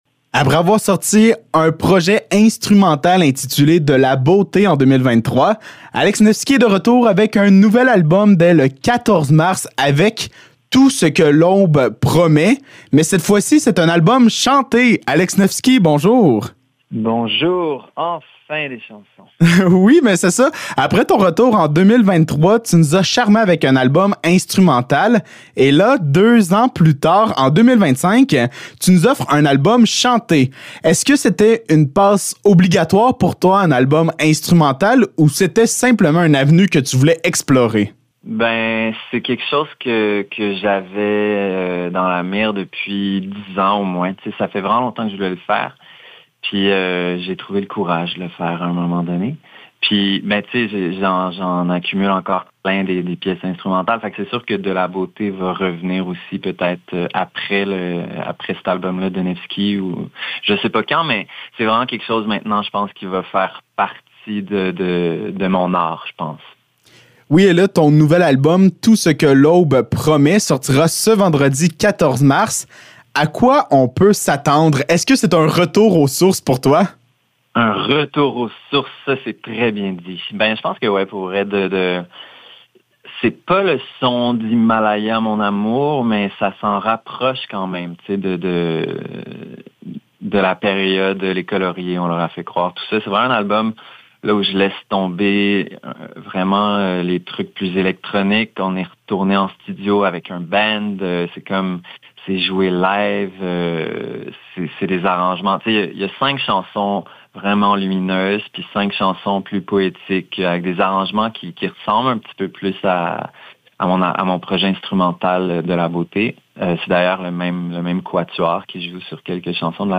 Entrevue avec Alex Nevsky